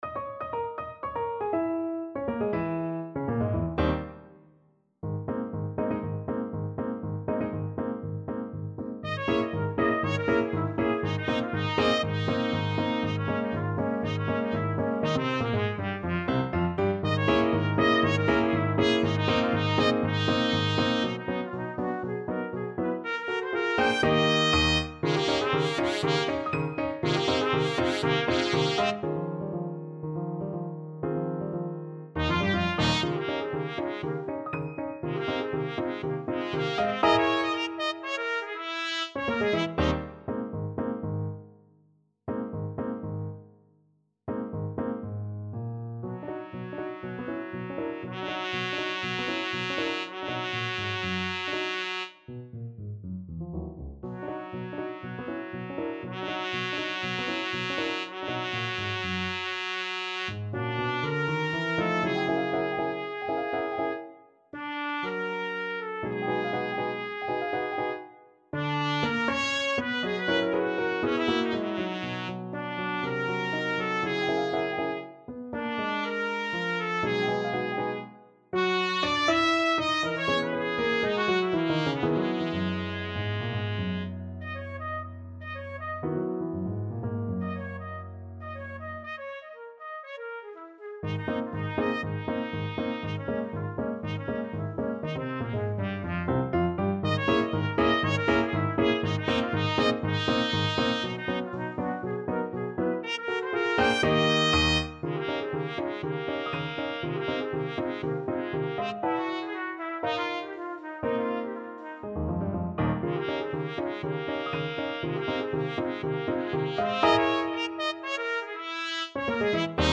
Trumpet version
Allegro giusto (View more music marked Allegro)
2/4 (View more 2/4 Music)
Classical (View more Classical Trumpet Music)